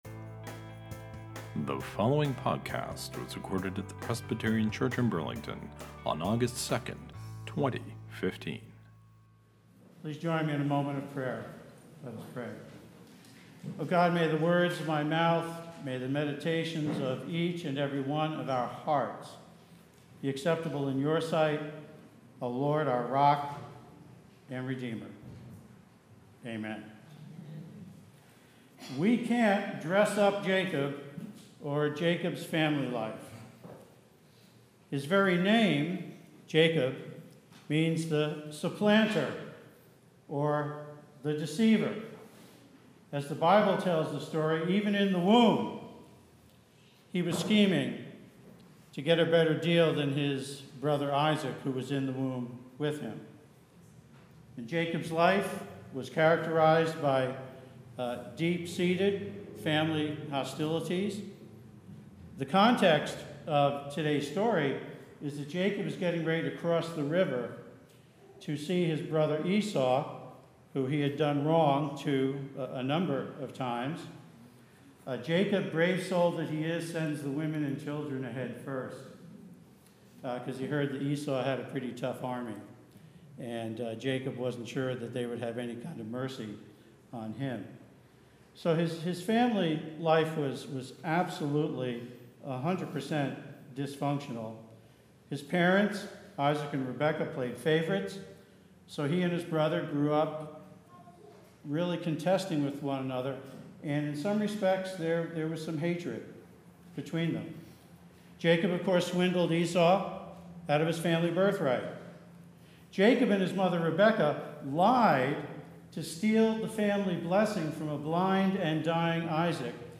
The sermon title for Sunday is "Will Wrestle for Food", a reference to both our Hebrew Scripture lesson from Genesis concerning Jacob and his wrestling match with God and our Gospel lesson in which Jesus speaks about the meaning of the feeding of the 5,000 people, Jacob's descendants, if you will, and their wrestling with…